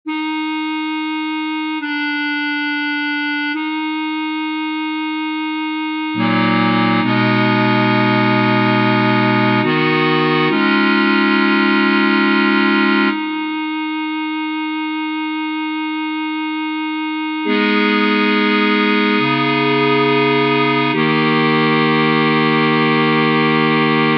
Key written in: E♭ Major
How many parts: 4
Type: Barbershop
Comments: Fun soft tag, perfect for an afterglow.
All Parts mix: